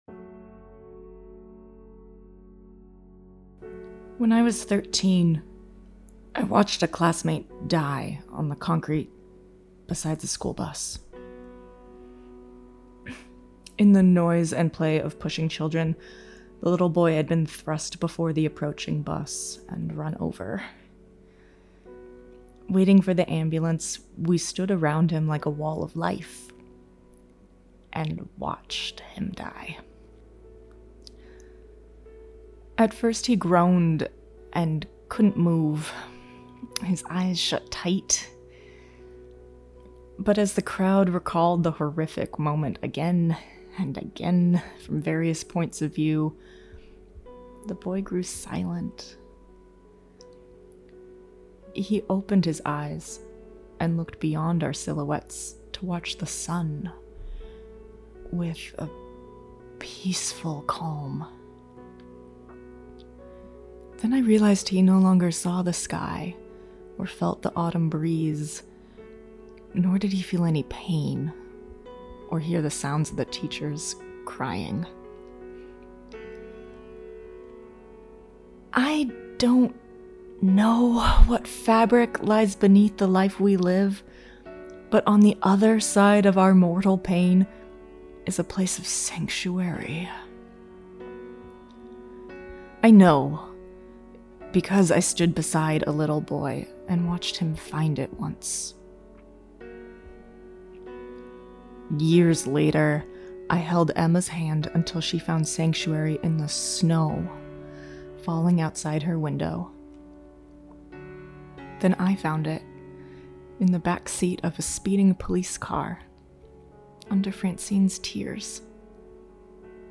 Strangers In Paradise – The Audio Drama – Book 7 – Episode 6 – Two True Freaks
The Ocadecagonagon Theater Group